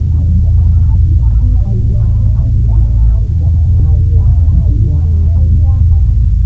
machinebass01.wav